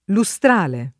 [ lu S tr # le ]